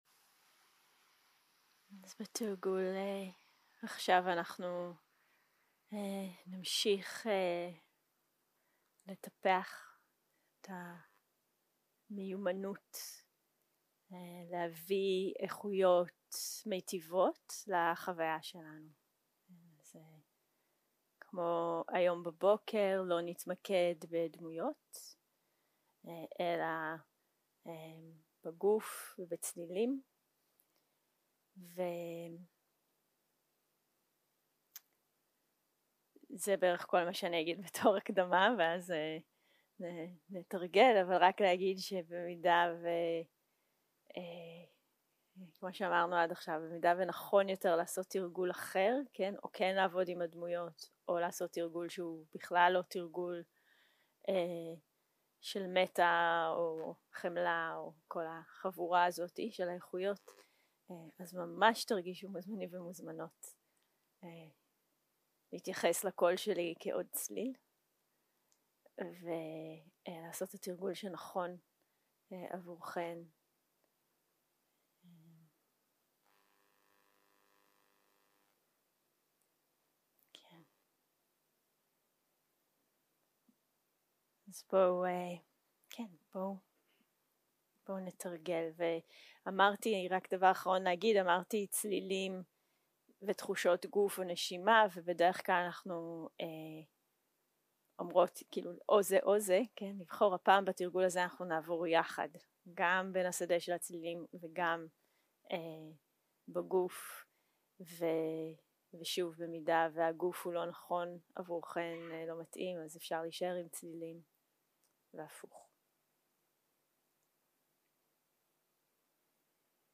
יום 6 - הקלטה 15 - צהרים - מדיטציה מונחית - A vast kind awareness (בעברית).
0:00 0:00 סוג ההקלטה: סוג ההקלטה: מדיטציה מונחית שפת ההקלטה: שפת ההקלטה: עברית